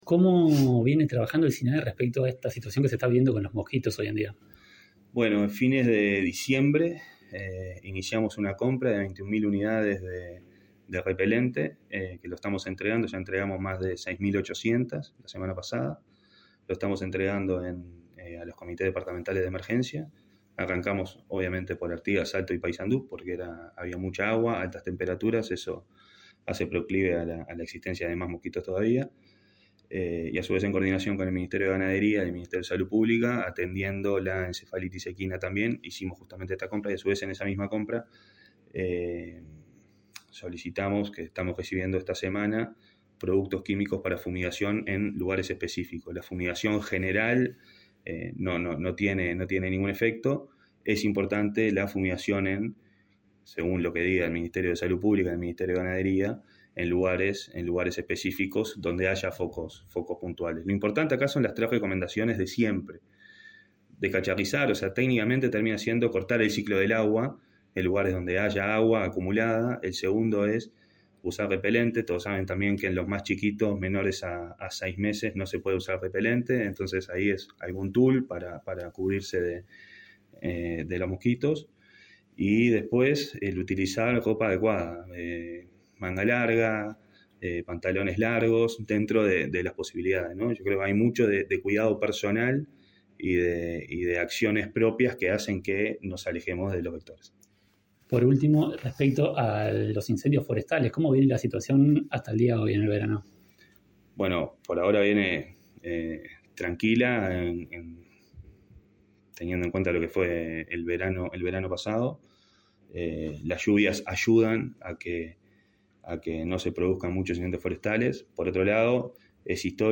Entrevista al director del Sinae, Santiago Caramés